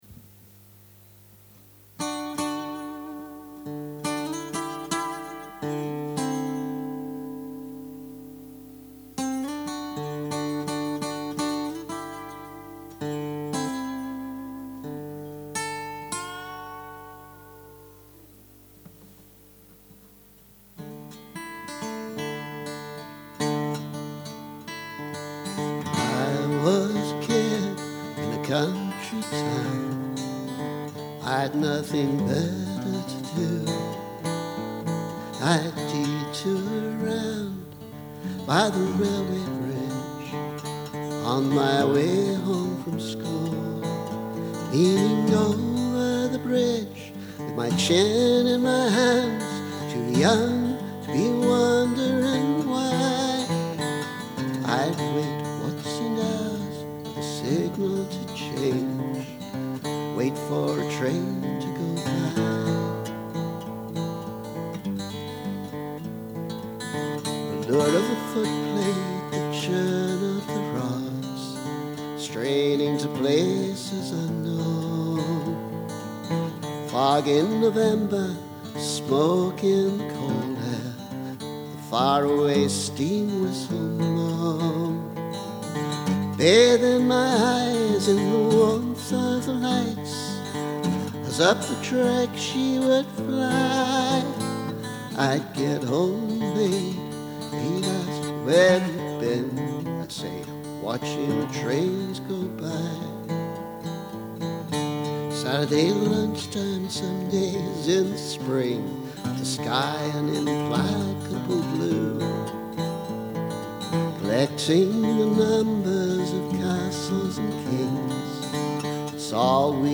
Latest draft, with no harmonies, but some guitar and mandolin: